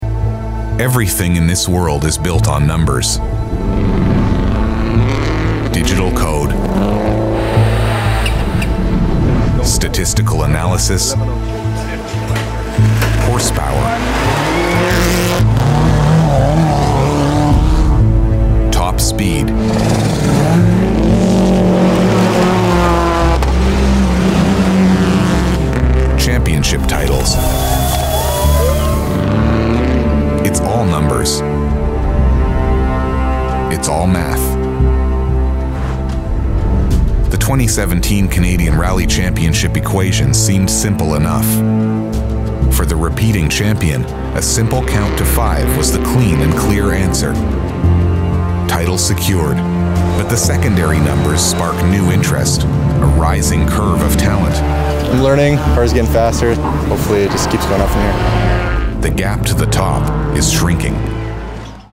deep, versatile Canadian English voice – from gritty and authoritative to upbeat and playful
Promos
UAD Apollo Twin, Neumann U87, Audio Technica AT 2050, Avid Pro Tools, Izotope Suite, Waves Suite, Logic Pro.
BaritoneBassDeep